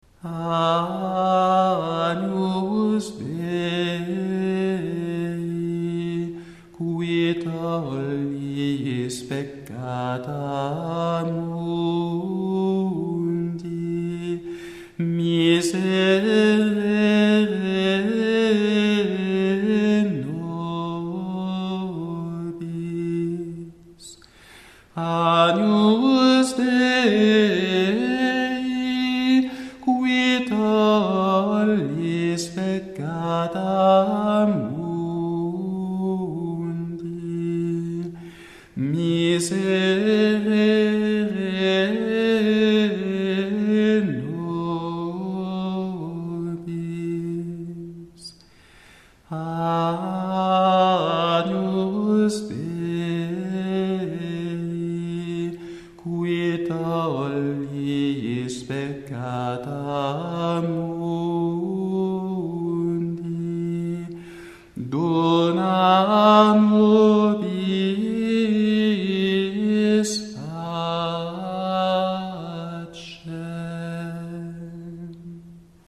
• grégorien croix introït offertoire agnus dei communion
Il s’agit d’un 6e mode mais qui n’utilise que des Sib : encore ne sont-ils que trois, sur l’invocation proprement dite de chaque Agnus, miserére nobis ou dona nobis pacem.
Tout est très simple, très modeste, jusque là.
On est bien vite revenu au calme souverain de ce triple Agnus.
On est donc en présence d’un Agnus très sobre, mais profond, humble et expressif, simple à chanter, et voilà sans doute la raison pour laquelle il demeure connu du Peuple de Dieu. Sa mélodie simple est populaire et facile à mémoriser.